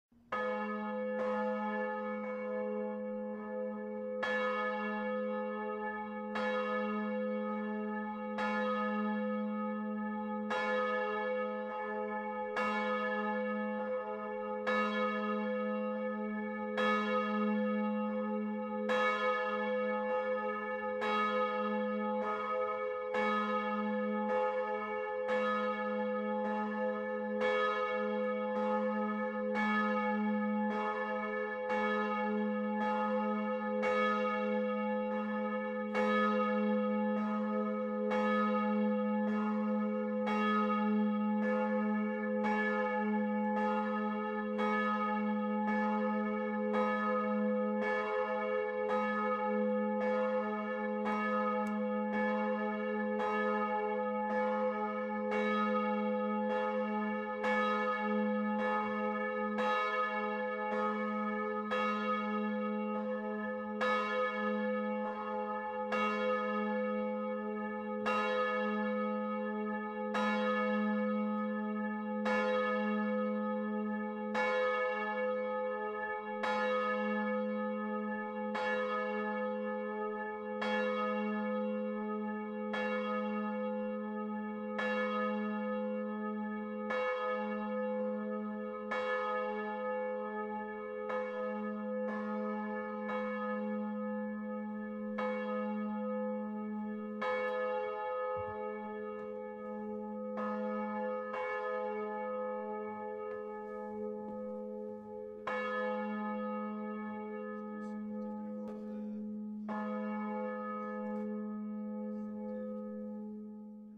Avec le logiciel Audacity  créer une petite séquence musicale à partir des sons des différentes cloches de la cathédrale de Strasbourg